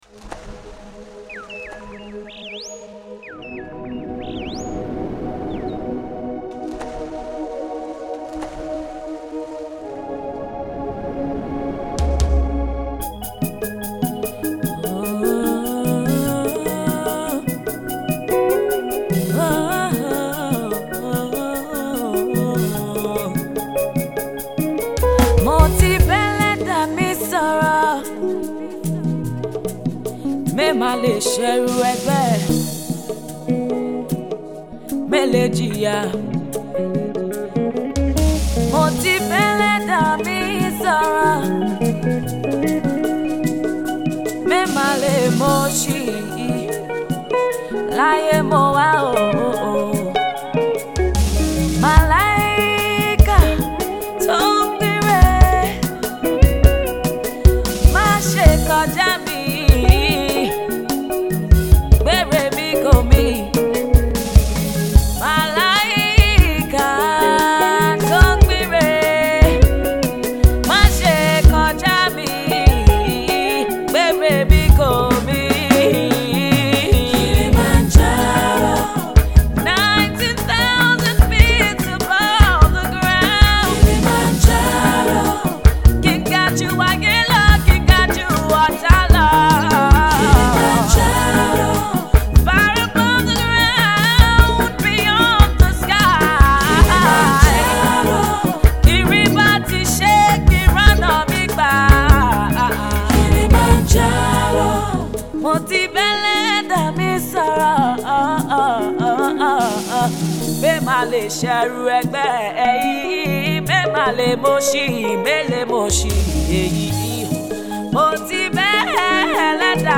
opted for a slower tempo melody
her melodious voice